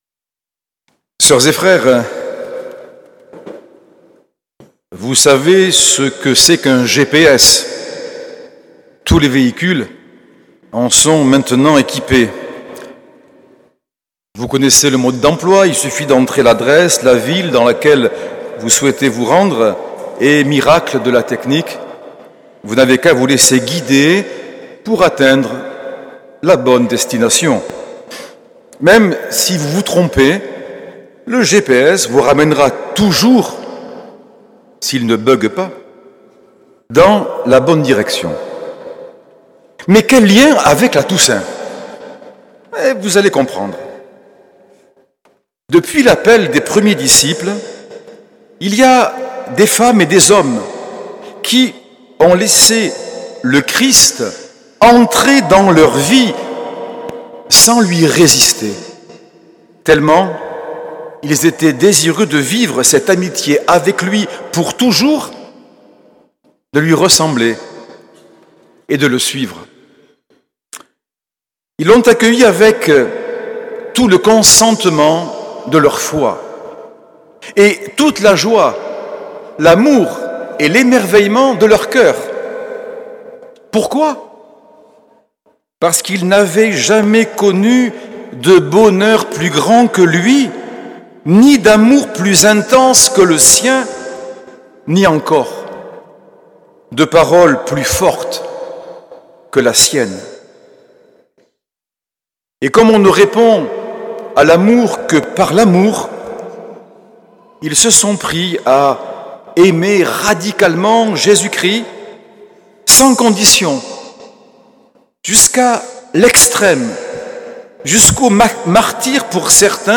Homélie de Monseigneur Norbert TURINI, solennité de la Toussaint, le 1er novembre 2024
L’enregistrement retransmet l’homélie de Monseigneur Norbert TURINI.